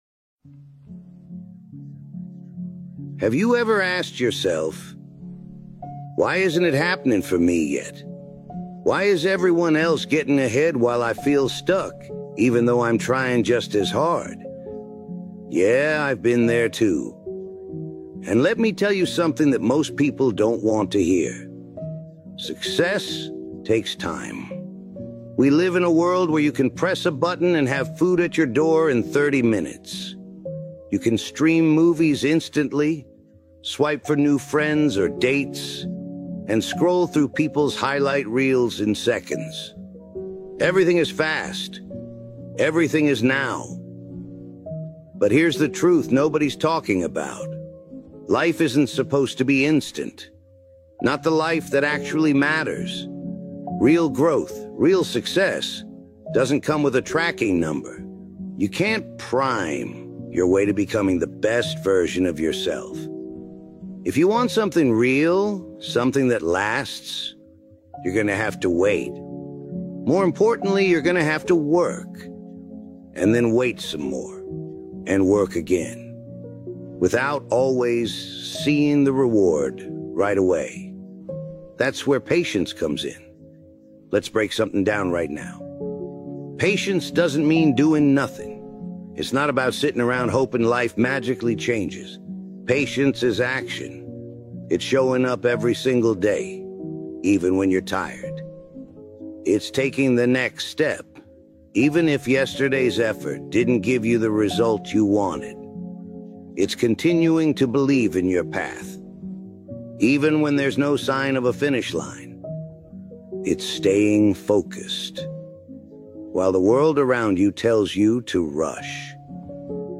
Hard Work Pays Off | Motivational Speech